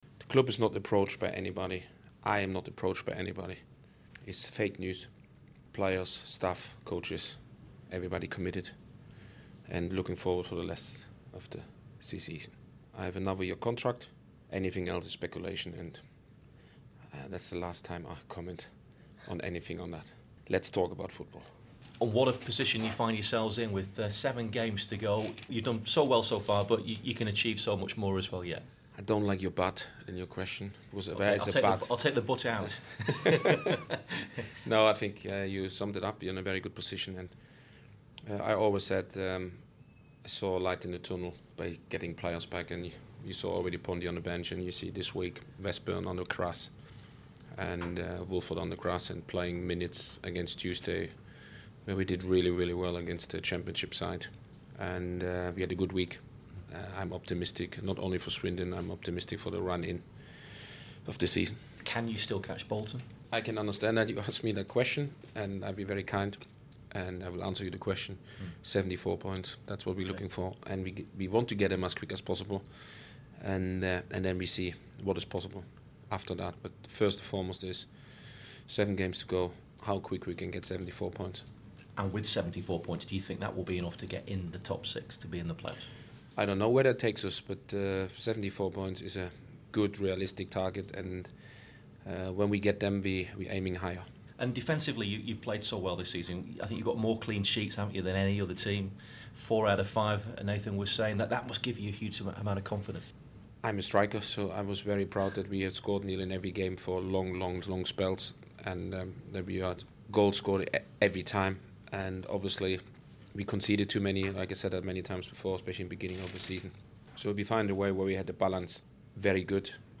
Fleetwood Head Coach, Uwe Rosler says on-line reports linking him with a move to Norwich is "fake news".